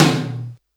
80s Digital Tom 05.wav